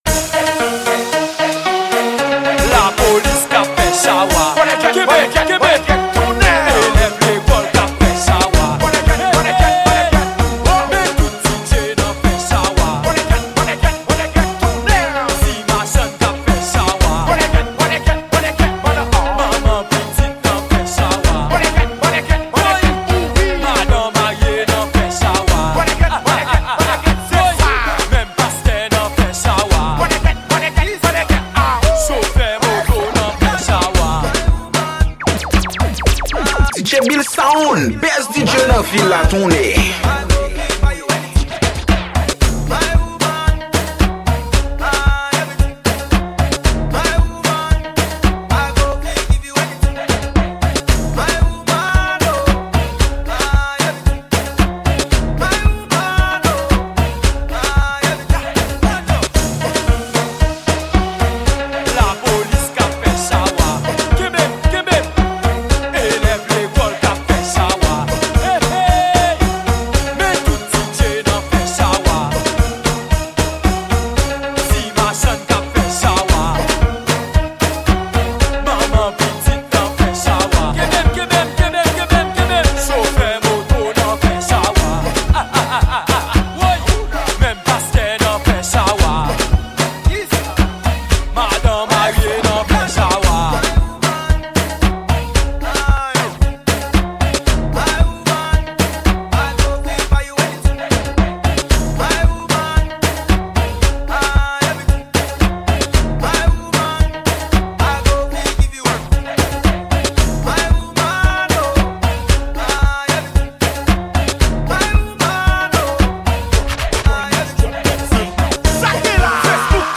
Genre: AFROBEATS.